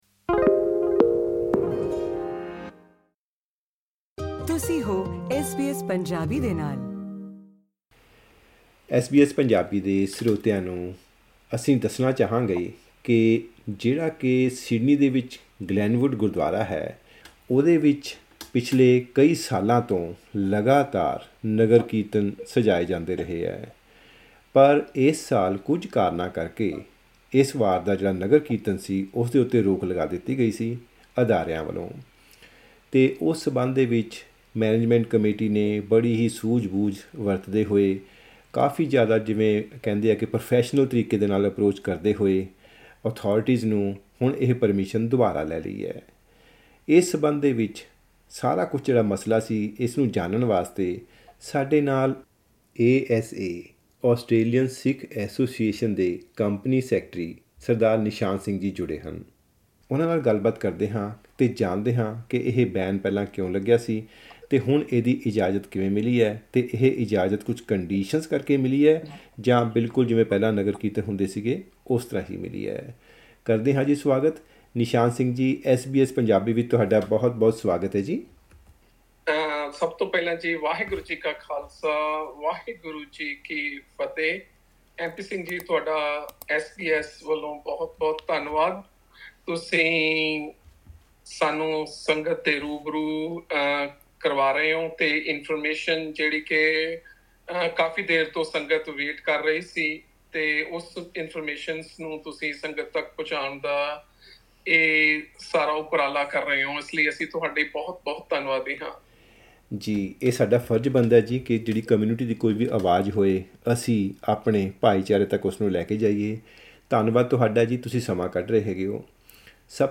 ਇਸ ਗੱਲਬਾਤ ਵਿੱਚ